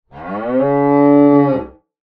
animalia_cow_random_3.ogg